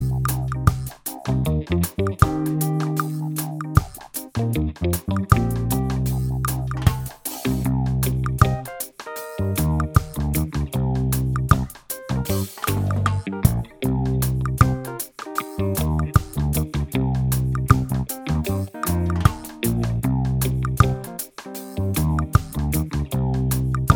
Minus Guitars Reggae 3:14 Buy £1.50